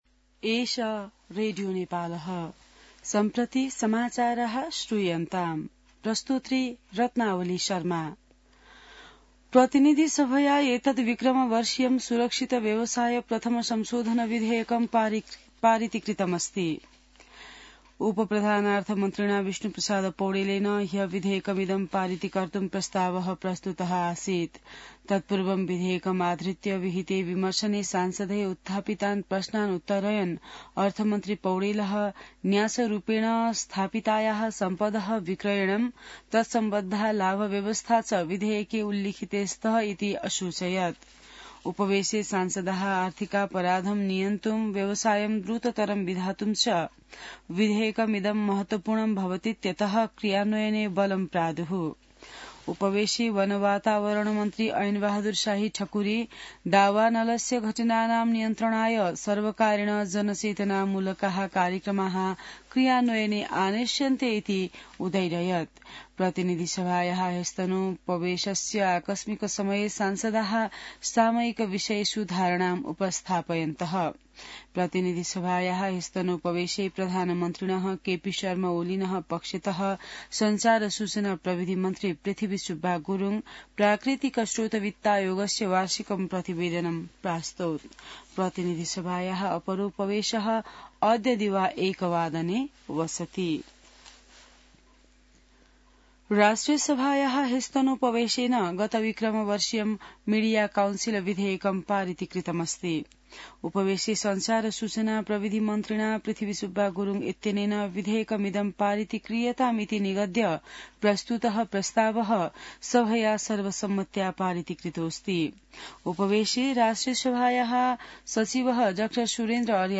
संस्कृत समाचार : ३० माघ , २०८१